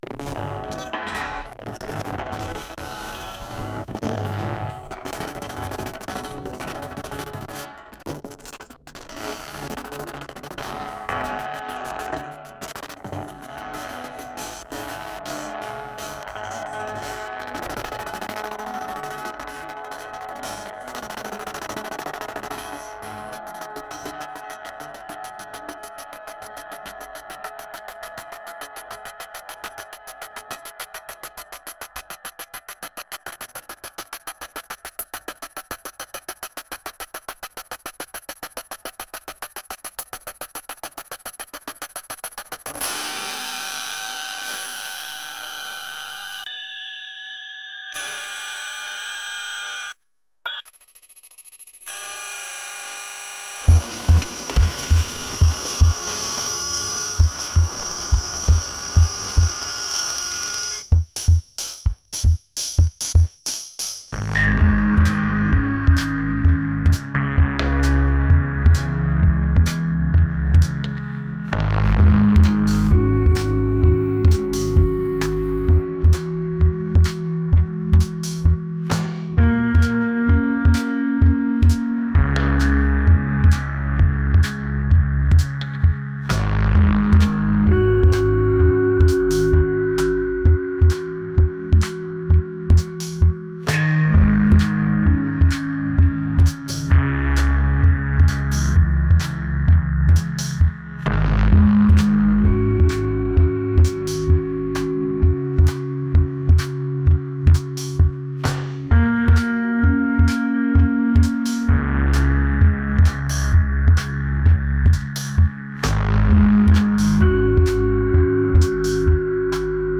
electronic